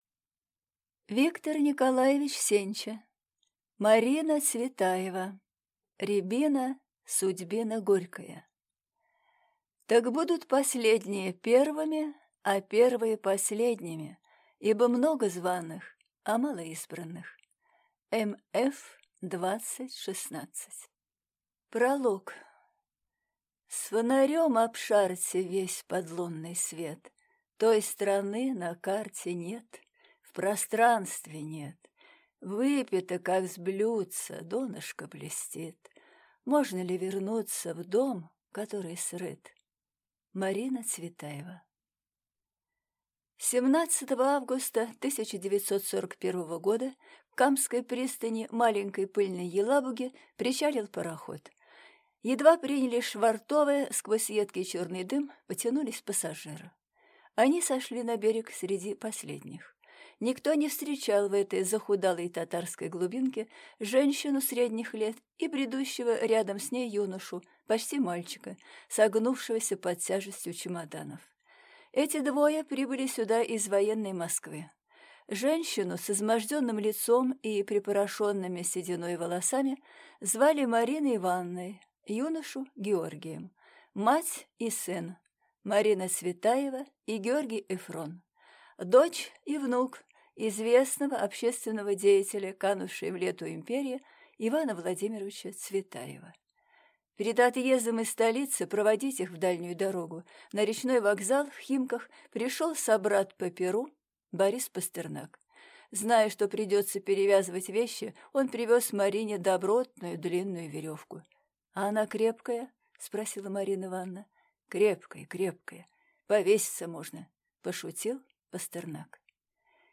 Аудиокнига Марина Цветаева. Рябина – судьбина горькая | Библиотека аудиокниг